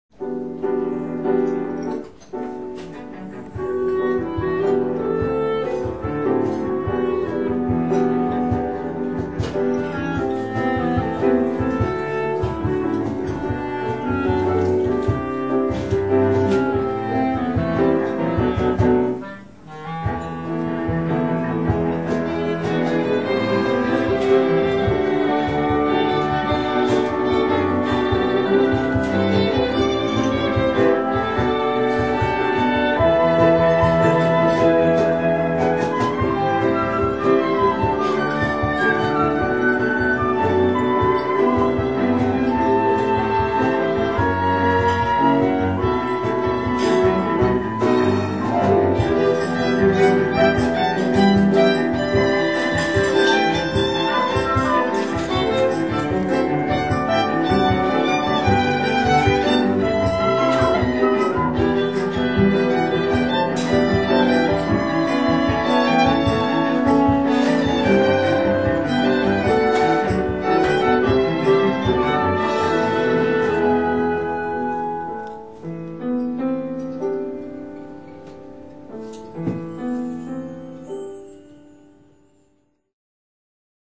Flute、Oboe、Clarinet、Sax、Cello、Piano